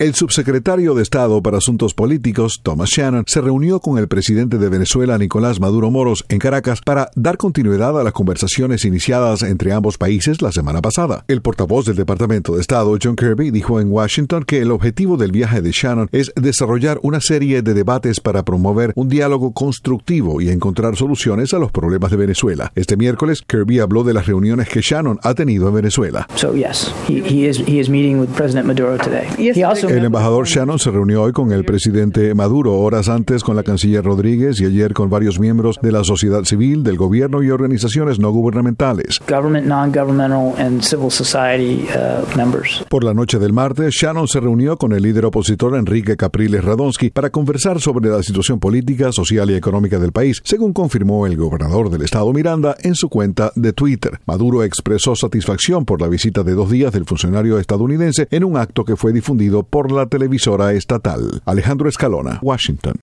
Declaraciones del portavoz del Departamento de Estado.